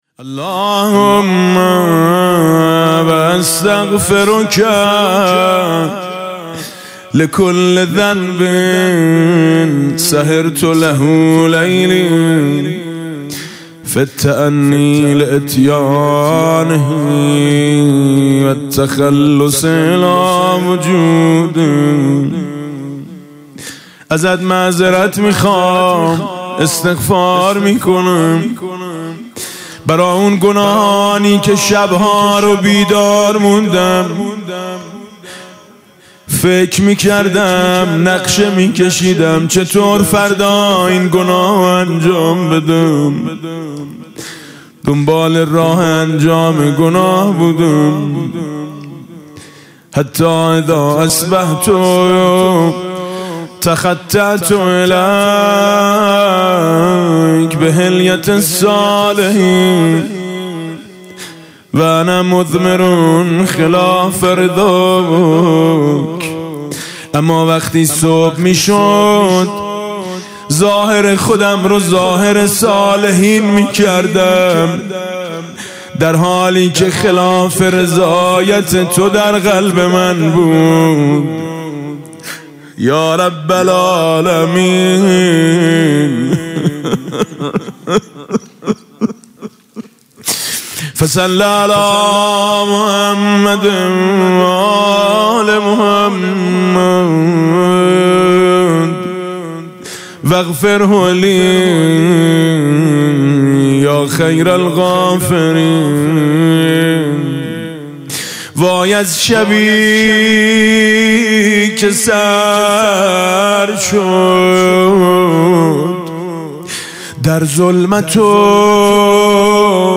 استغفار هفتاد بندی امام علی علیه السلام با نوای میثم مطیعی + متن (بند نهم) | ضیاءالصالحین
استغفار هفتاد بندی امام علی علیه السلام با نوای میثم مطیعی + متن (بند نهم) در این بخش از ضیاءالصالحین، نهمین بخش استغفار هفتاد بندی حضرت امیرالمومنین امام علی علیه السلام را با نوای حاج میثم مطیعی به مدت سه دقیقه با اهل معرفت و علاقه مندان به دعا و مناجات به اشتراک می گذاریم.